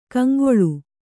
♪ kaŋgoḷu